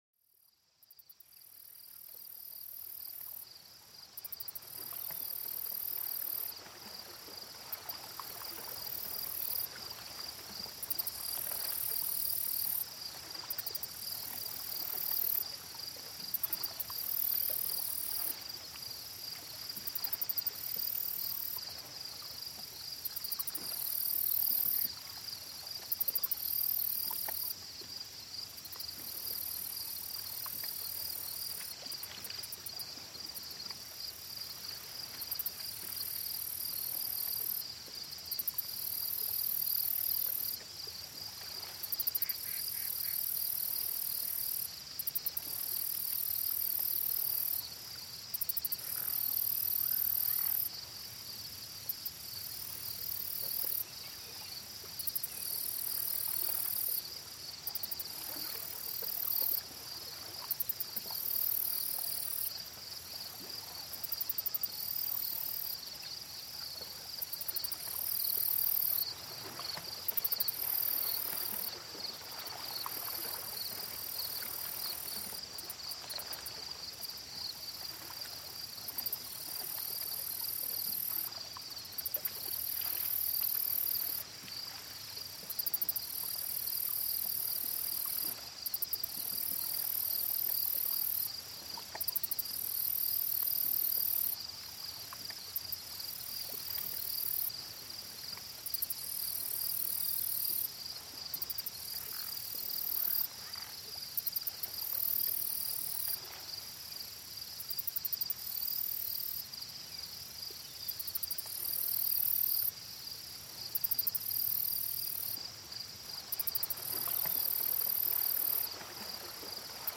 Plongez dans une ambiance apaisante, bercée par les doux clapotis de l'eau et le chant des oiseaux au bord d'un lac. Chaque son invite à la contemplation, recréant l’harmonie parfaite d’un refuge naturel.